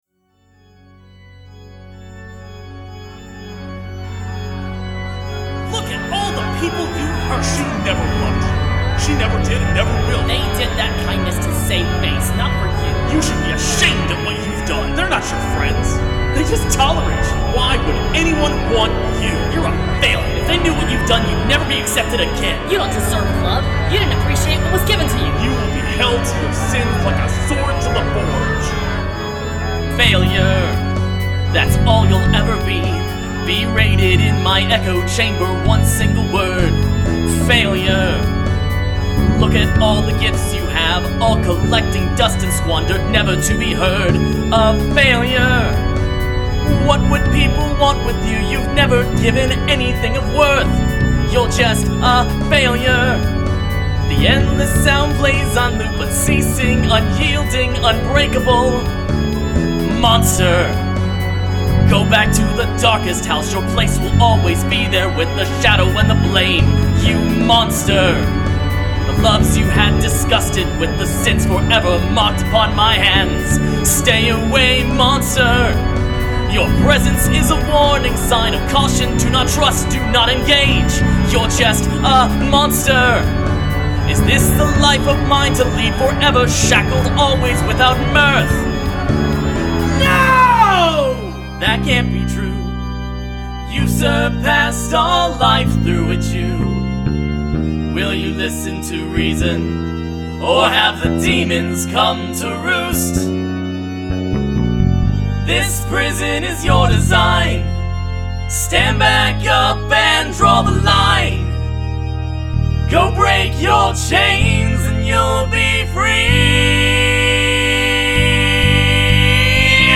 Call it a vent piece.